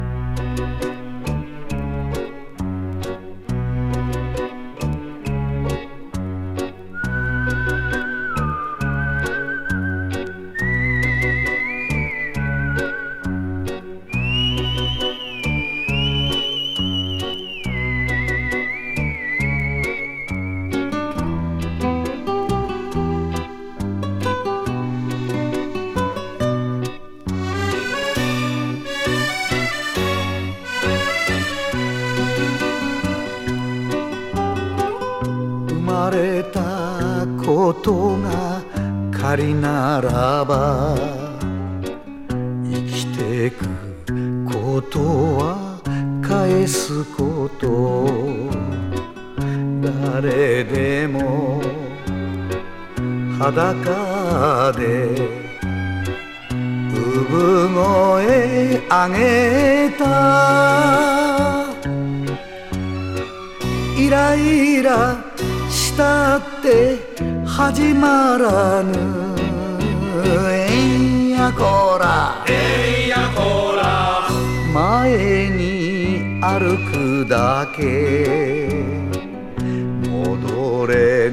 しんみり聴きたい演歌。